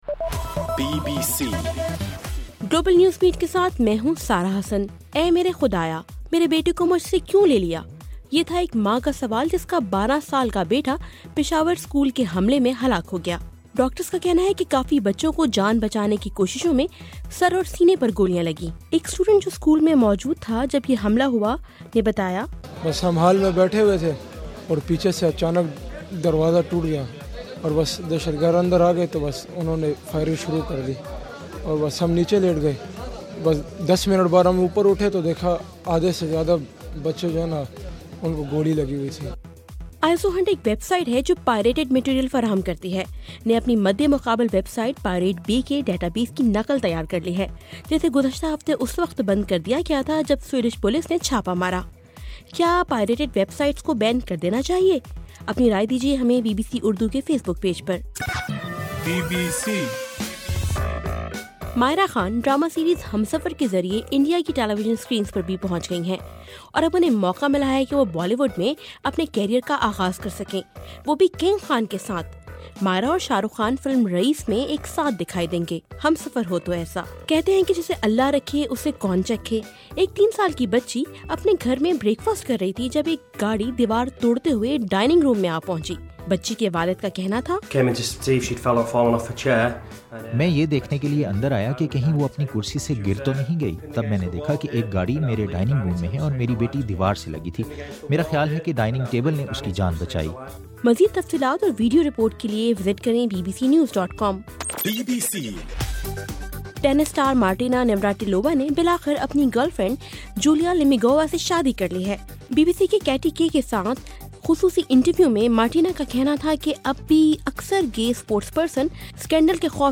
دسمبر 16: رات 12 بجے کا گلوبل نیوز بیٹ بُلیٹن